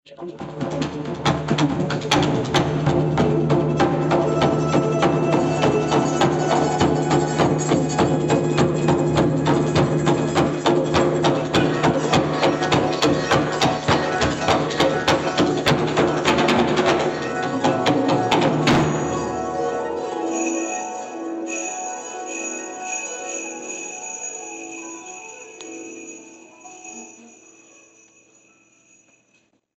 traditional Japanese taiko drumming